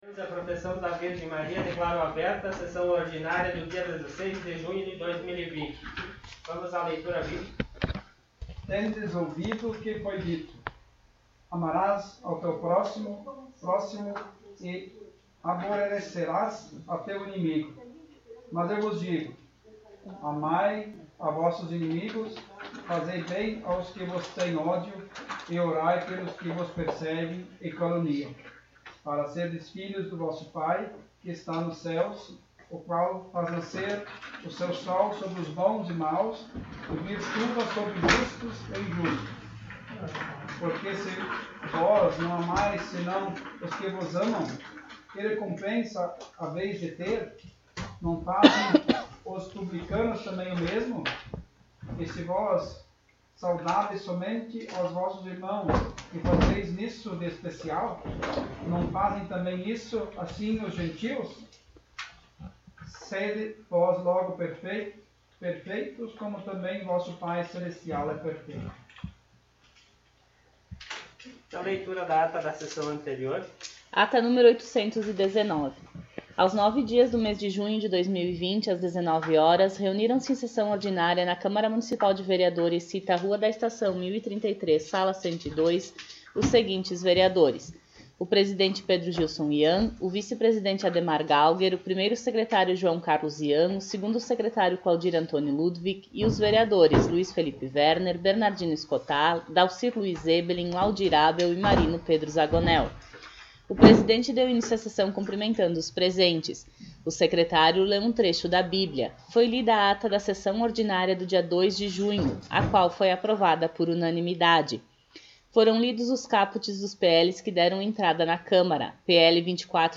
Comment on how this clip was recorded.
Sessão Ordinária do dia 16 de junho de 2020